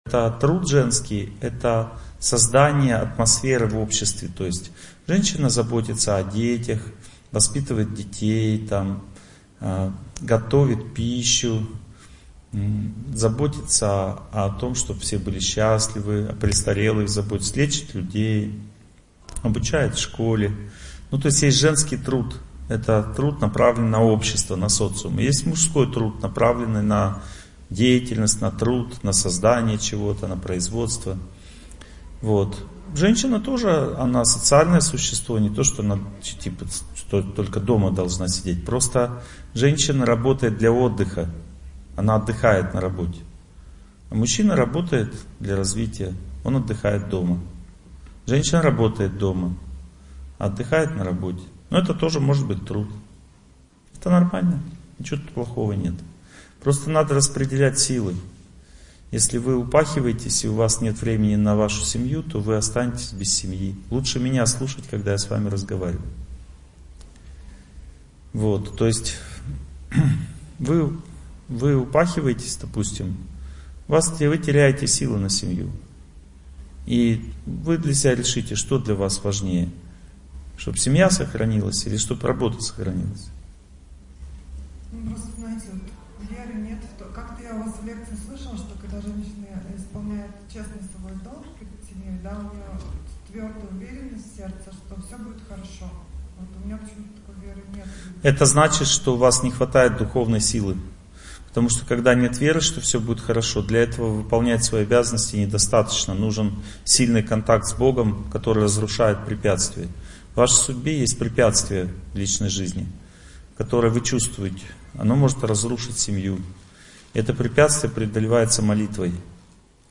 Счастливая семья. Лекция 2